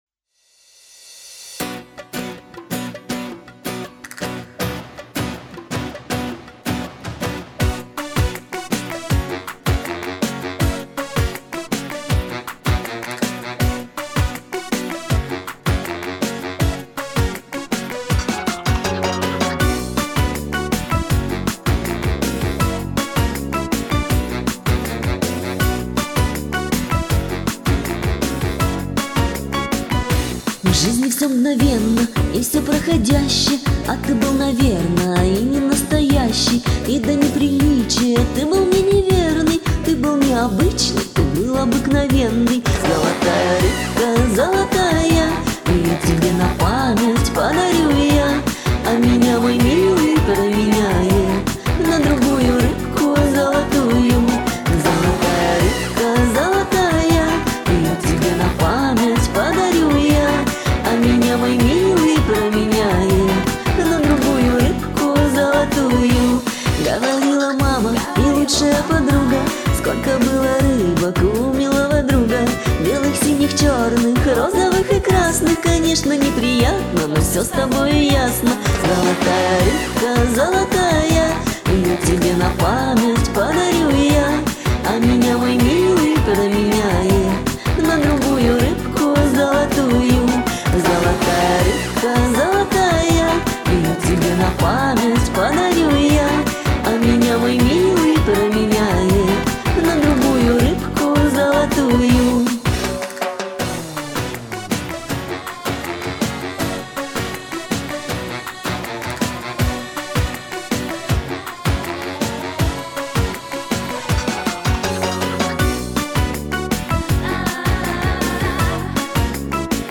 Голос хорош и уверен!